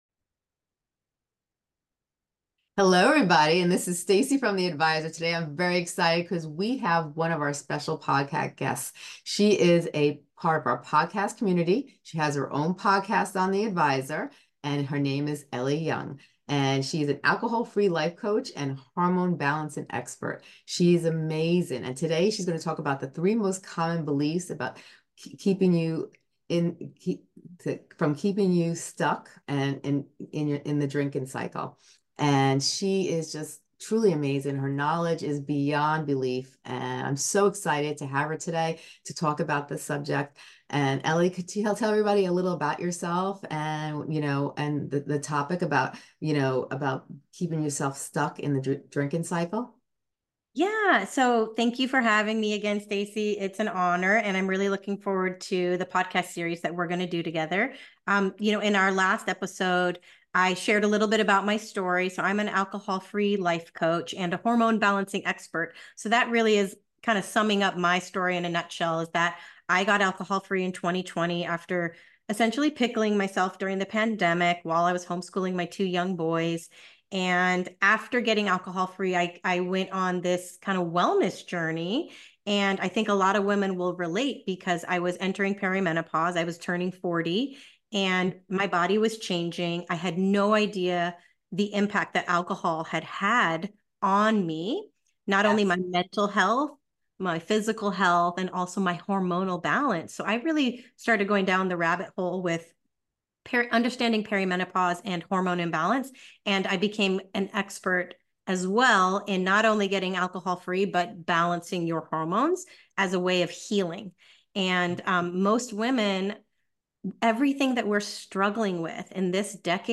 In this episode, uncover the 3 most common beliefs that keep you trapped in the cycle of drinking, and learn powerful strategies to break free and regain control of your life. Don't miss this insightful conversation that will challenge your perspective and inspire positive change.